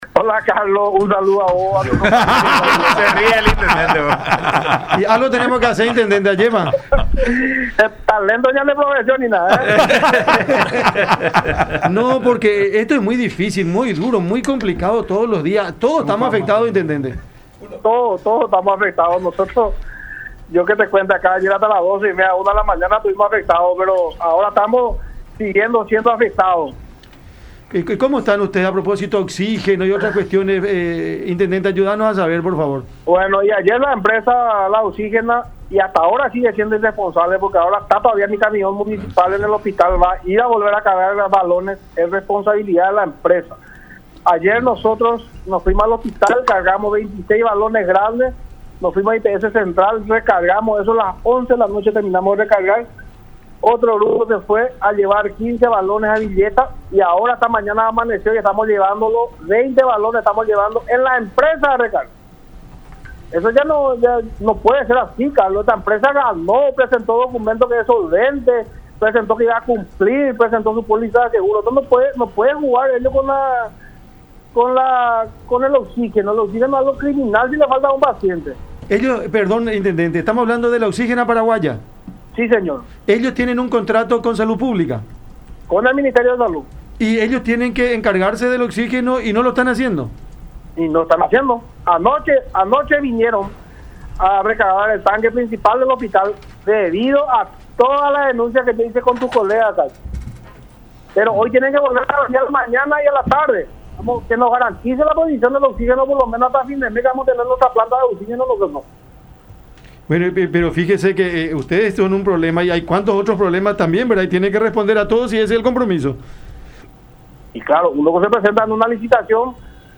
Es criminal que le falte oxígeno a un paciente”, criticó el jefe comunal también en conversación con La Unión.